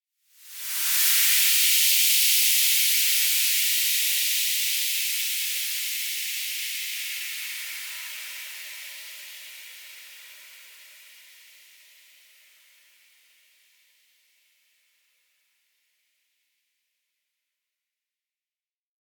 Index of /musicradar/shimmer-and-sparkle-samples/Filtered Noise Hits
SaS_NoiseFilterD-02.wav